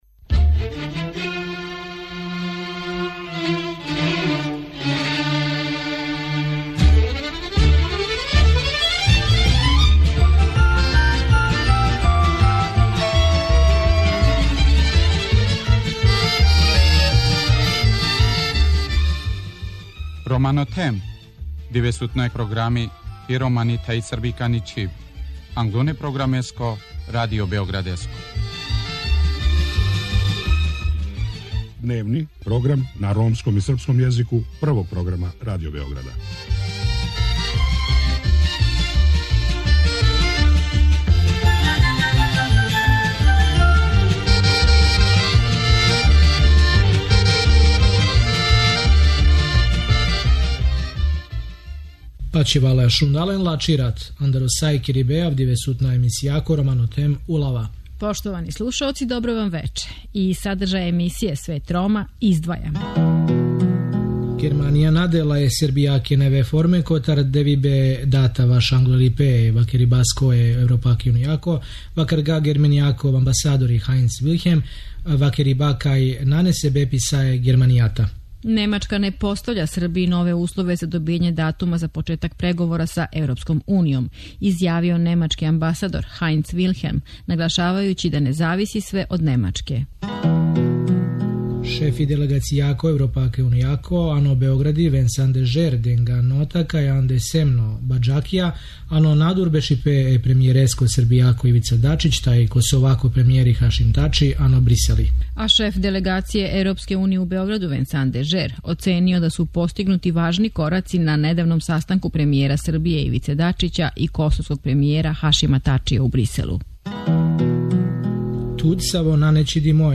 У другом делу емисије разговарамо са председником Националног савета ромске националне мањине у Србији Витомиром Михајловићем о активностима савета .